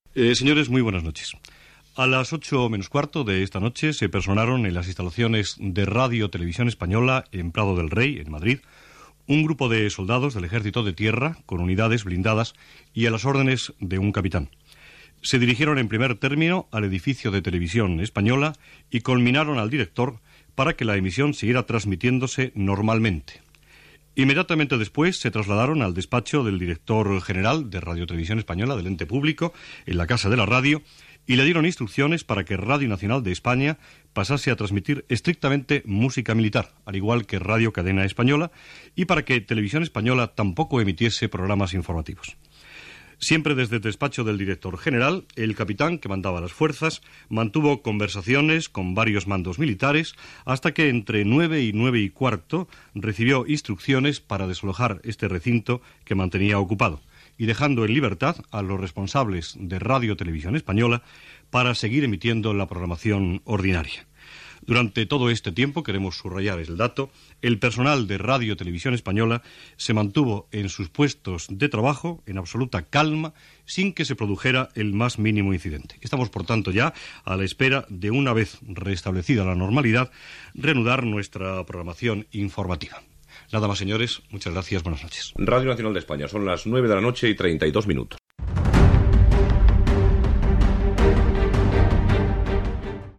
El director de RNE, Eduardo Sotillo, informa de la presència de l'exèrcit espanyol a la Casa de la Radio, seu de RNE, a les 19:45. Després d'haver-se programa marxes militars, represa de la programació informativa, a les 21:32 hores.
Informatiu